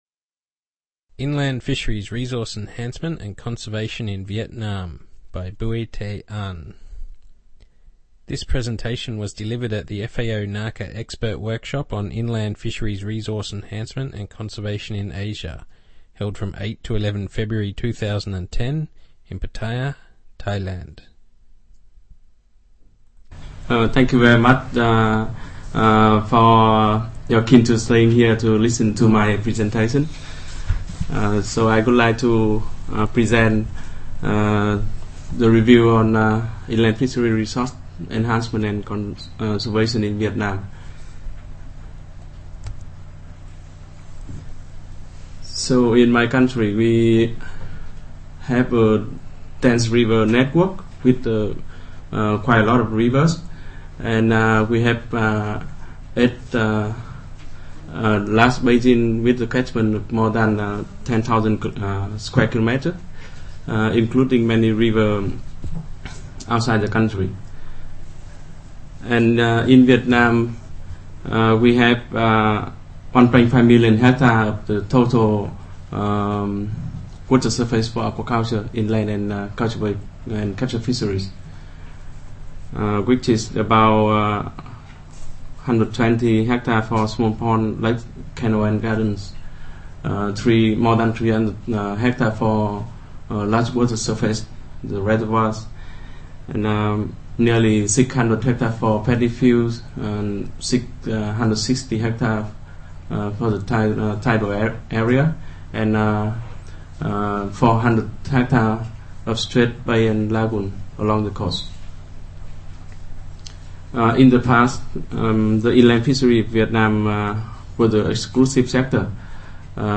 Presentation on inland fisheries resource enhancement and conservation in Vietnam